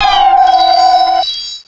pokeemerald / sound / direct_sound_samples / cries / cresselia.aif